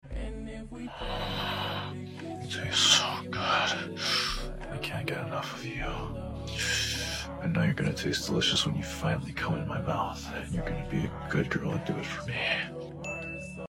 His Deep Voice Makes Me Sound Effects Free Download